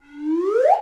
snd_slidewhistle.wav